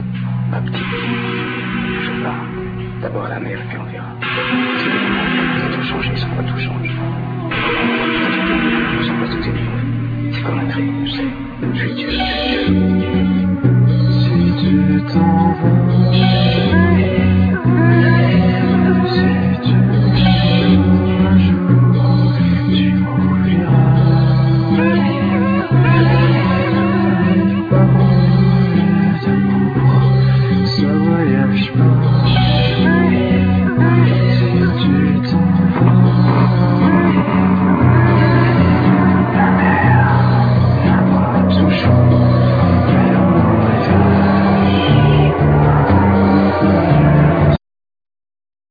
Bass-gitar
Drums,Percussions
Voice,Sampler,Djembe
Clarinet
Mong guitar